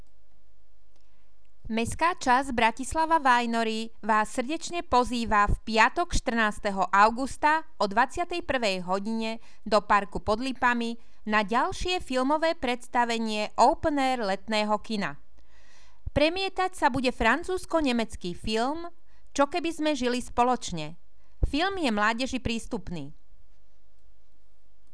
Hlásenie rozhlasu